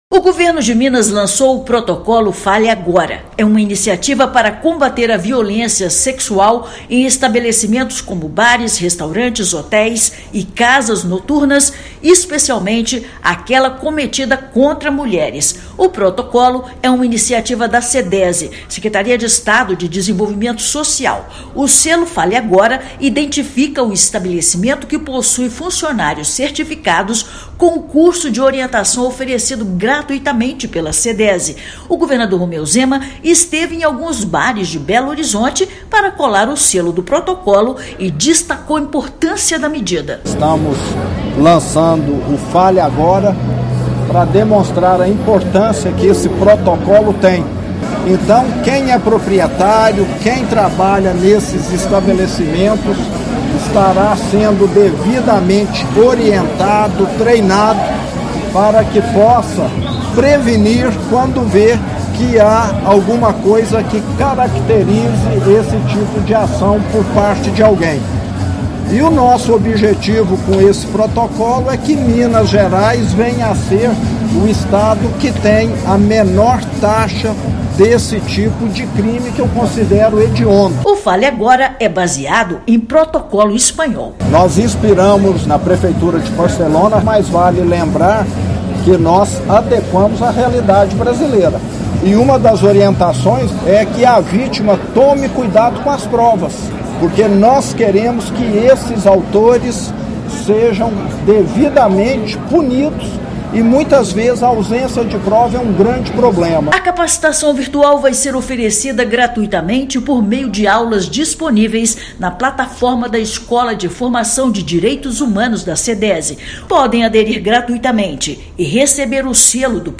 Projeto Fale Agora vai capacitar funcionários de estabelecimentos comerciais para prevenir violências sexuais, acolher vítimas e orientar para atendimento adequado. Ouça matéria de rádio.